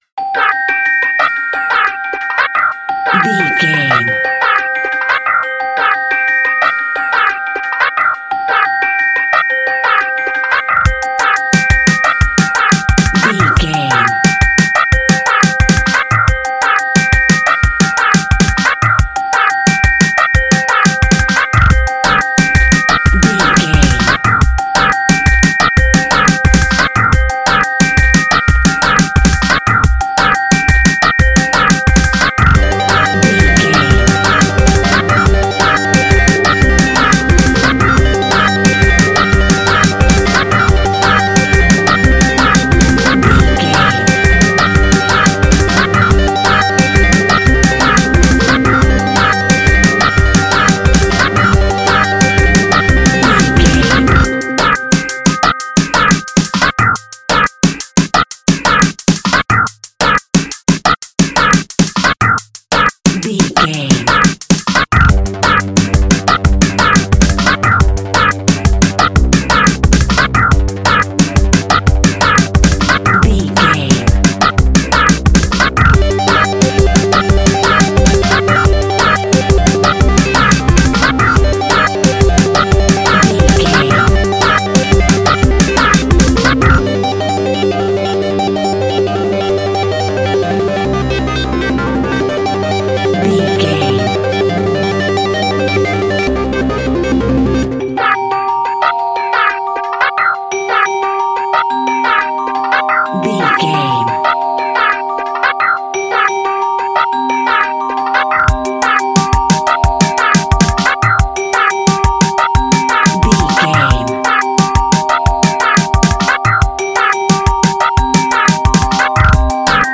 Aeolian/Minor
Fast
futuristic
hypnotic
industrial
frantic
aggressive
powerful
drum machine
synthesiser
sub bass
synth leads
synth bass